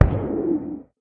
guardian_hit4.ogg